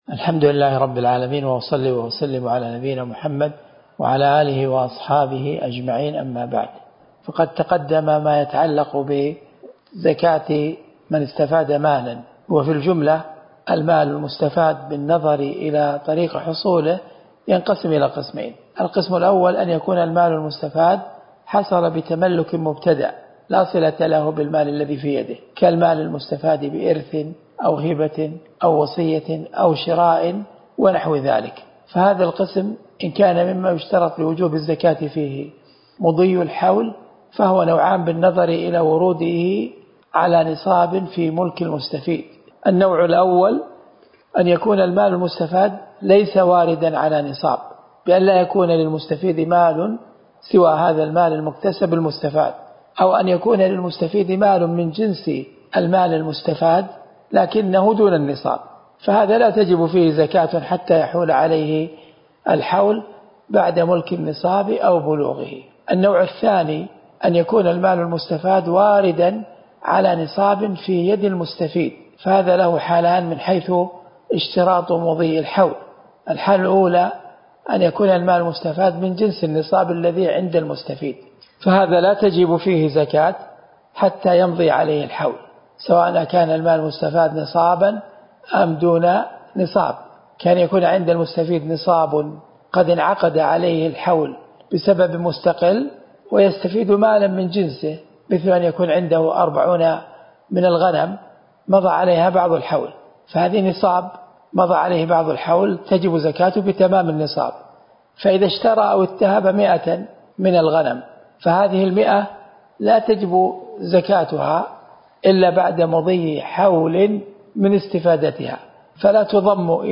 الدرس (3) من شرح كتاب الزكاة من الروض المربع